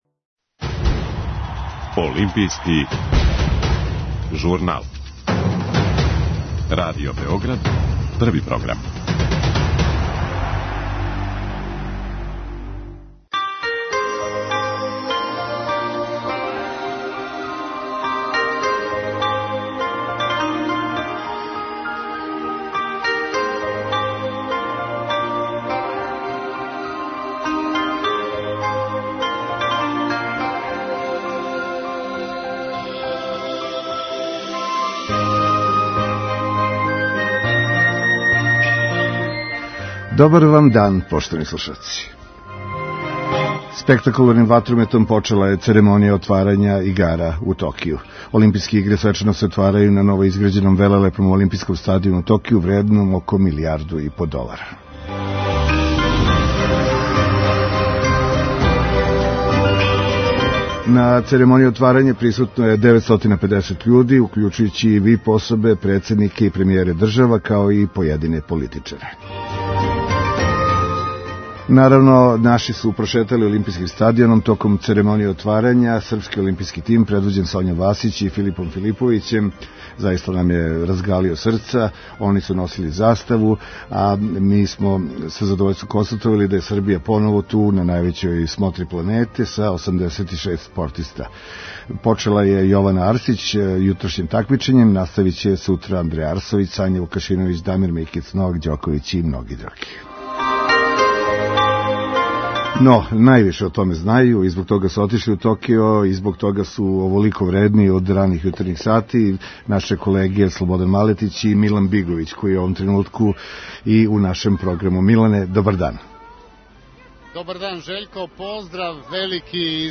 [ детаљније ] Све епизоде серијала Аудио подкаст Радио Београд 1 Из другог угла Поштована децо Породична прича Из тинејџ угла "Вечне хемикалије" откривене у храни у Европи, има ли их и код нас?